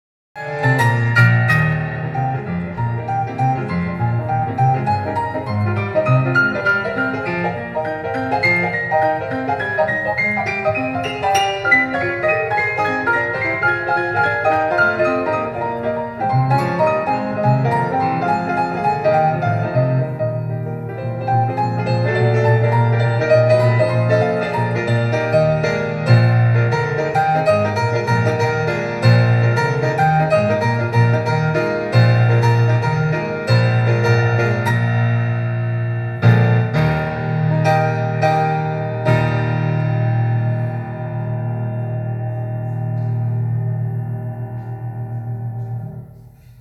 This is still my fav live version of the leaps: